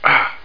1 channel
00013_Sound_ahhh.mp3